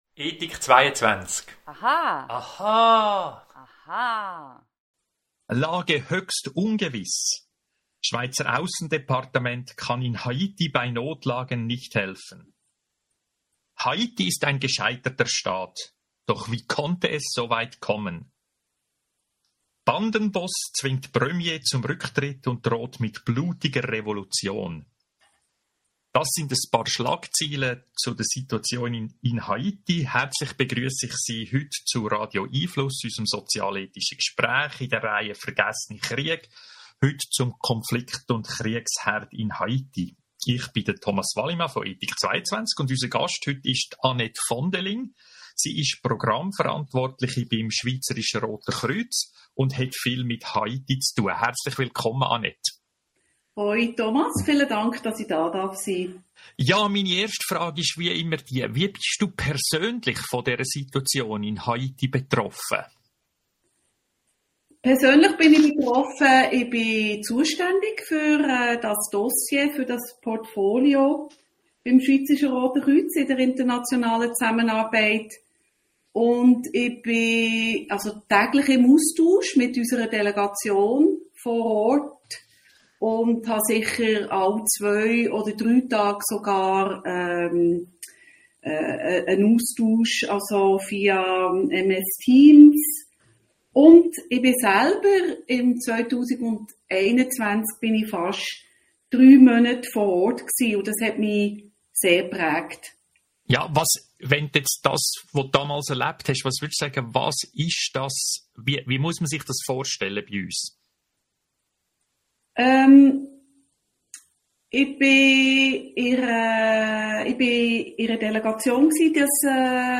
Podcast hier zuhören Radio🎙einFluss findet jeden Mittwoch 18:30 - 19 Uhr statt.